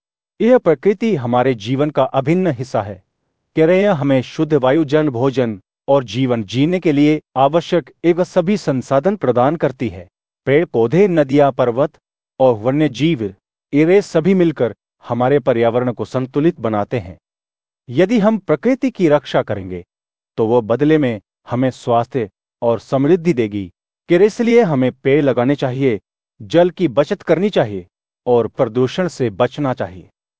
techno_2e1498ea.wav